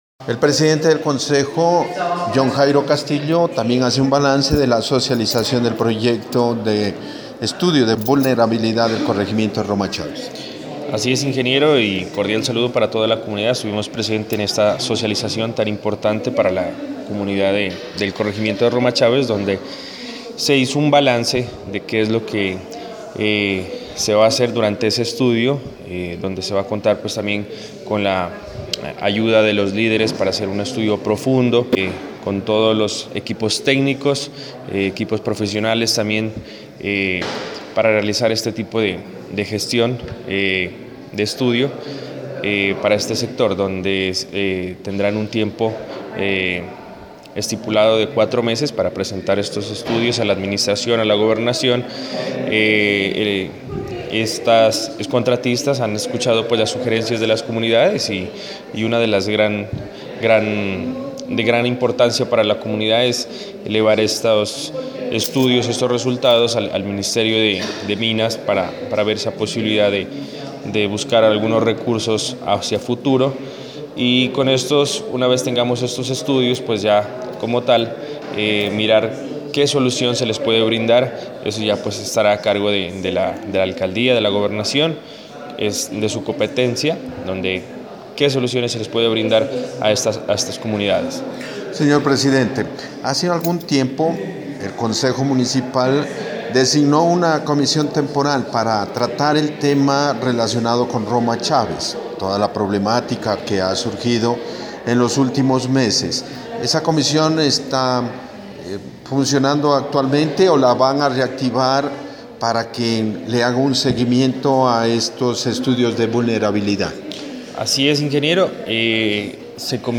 Presidente del Concejo John Jairo Castillo